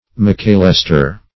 mcalester.mp3